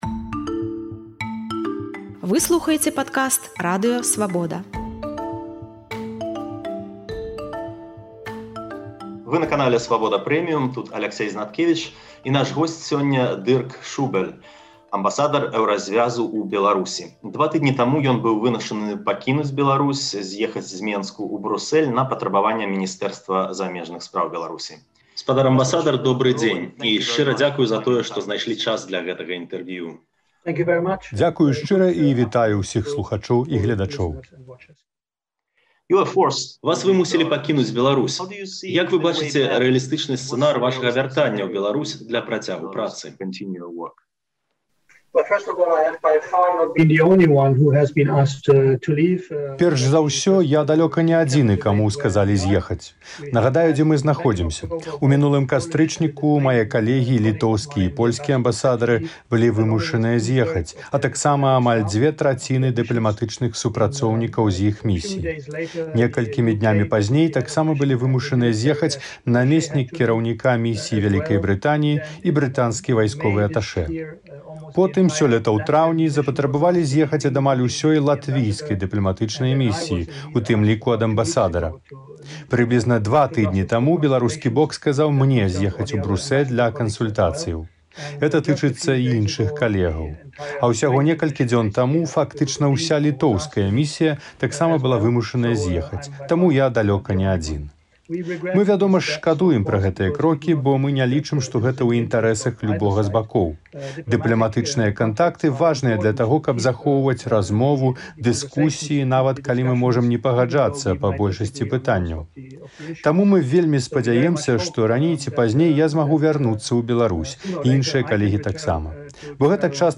Дырк Шубэль, амбасадар Эўразьвязу ў Беларусі, два тыдні таму быў вымушаны зьехаць зь Менску ў Брусэль на патрабаваньне беларускага Міністэрства замежных спраў. У гутарцы з Радыё Свабода ён расказаў, як будзе працягваць сваю працу, якія высновы зрабіў з кантактаў з амбасадарам Расеі і ў якіх сфэрах можа працягвацца супрацоўніцтва паміж Эўразьвязам і цяперашнімі ўладамі Беларусі.